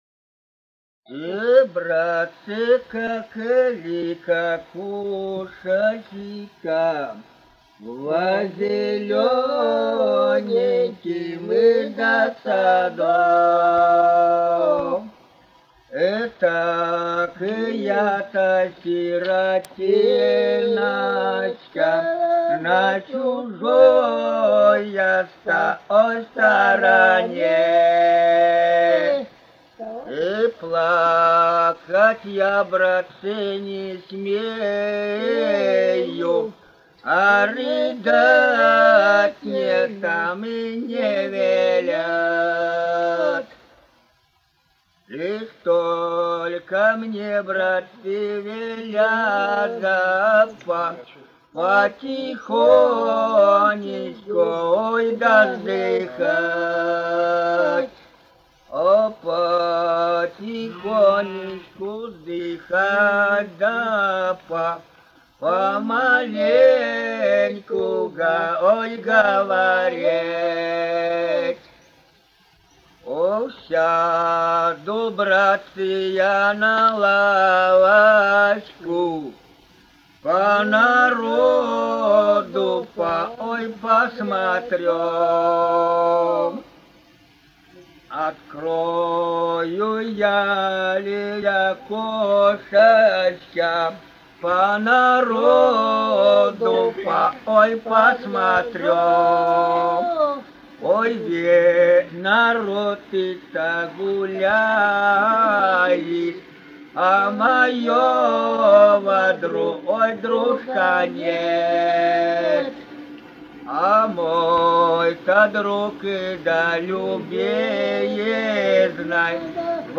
с. Коробиха Катон-Карагайского р-на Восточно-Казахстанской обл.